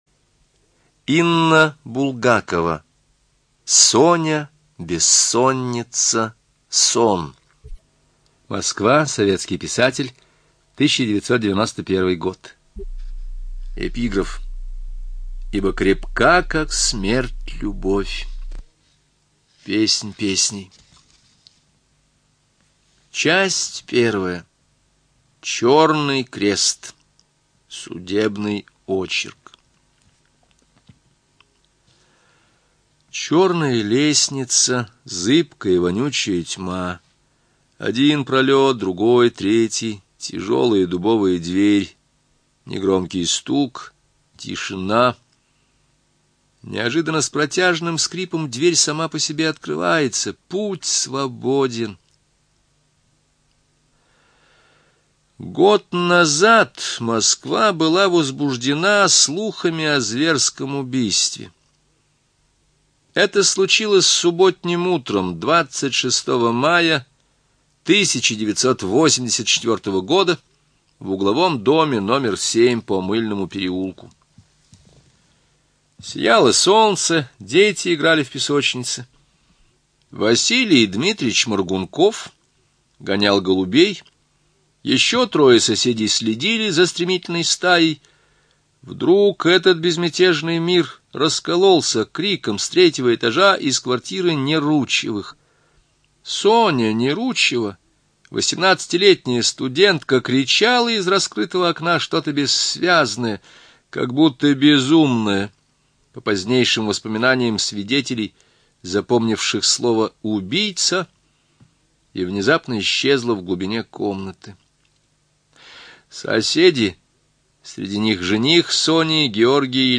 ЖанрДетективы и триллеры
Студия звукозаписиЛогосвос